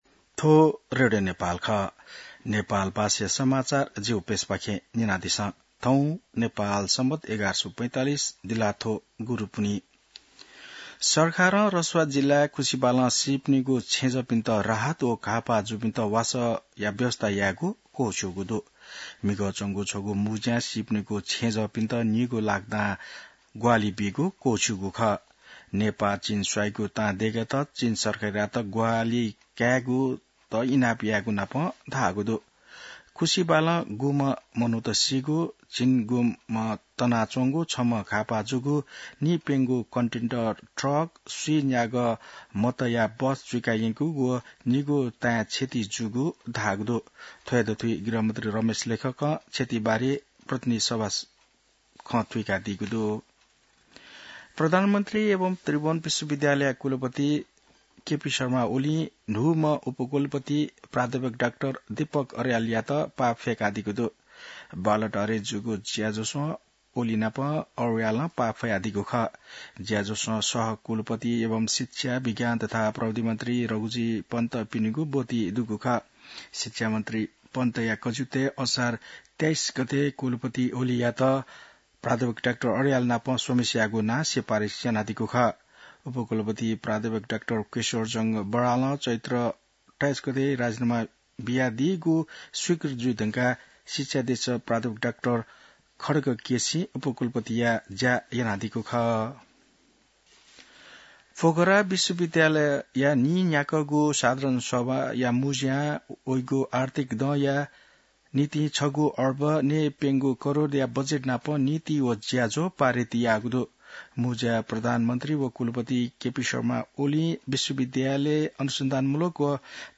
नेपाल भाषामा समाचार : २६ असार , २०८२